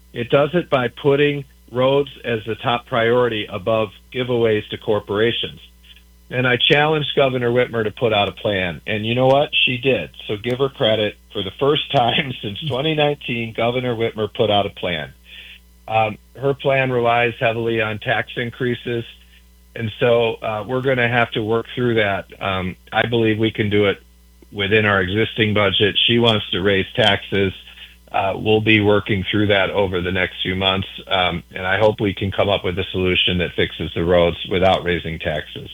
AUDIO: House Speaker Hall reacts to Governor Whitmer’s road funding plan